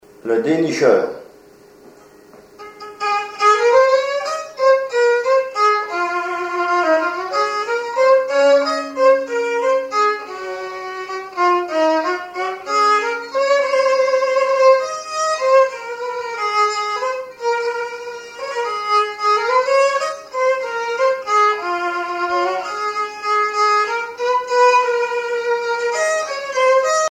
violoneux, violon
danse : valse musette
Genre strophique
Pièce musicale inédite